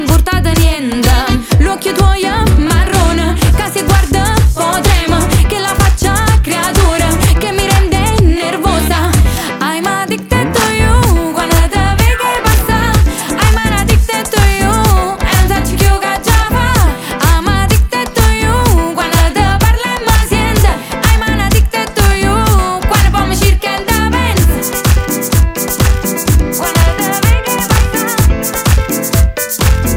Pop Latino, Latin